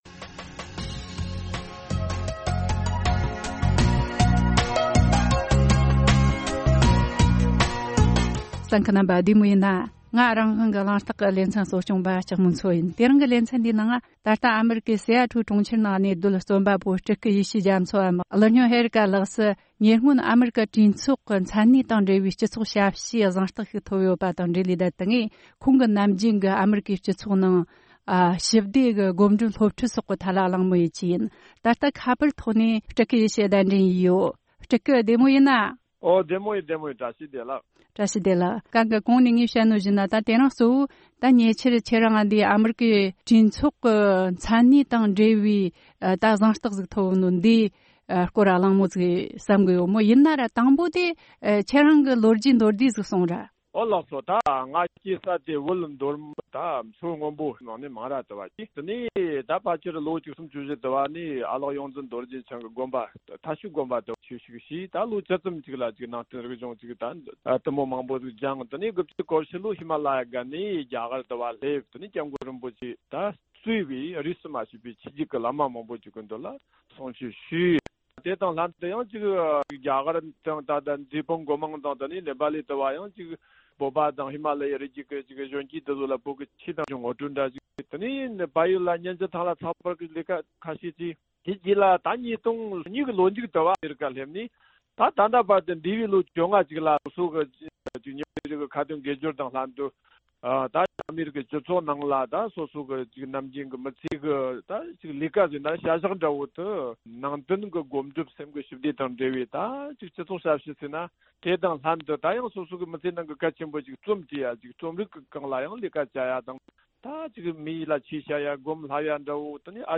གསར་འགོད་པ